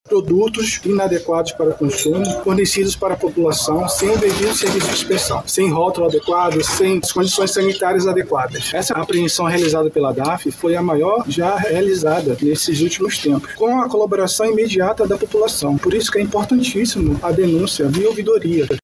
SONORA-2-APREENSAO-ALIMENTOS-VENCIDOS-.mp3